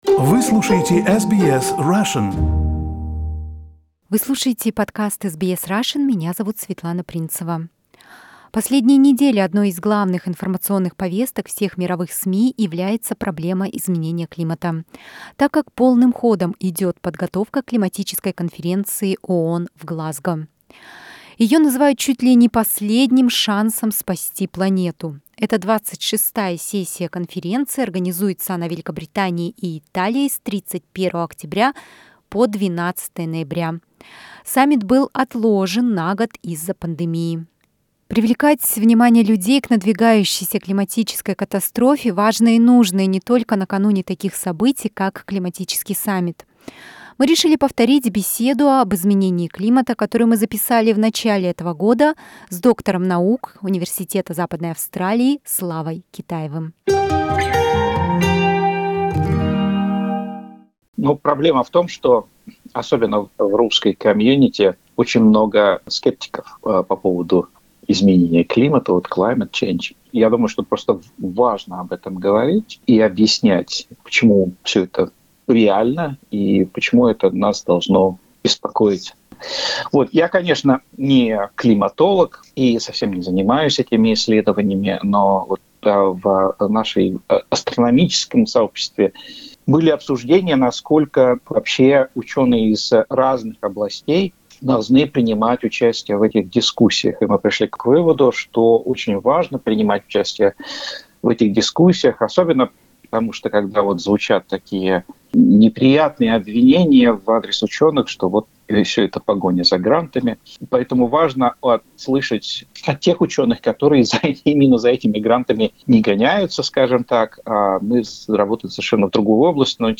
Изменение климата: беседа
Доступно и интересно: беседа об изменении климата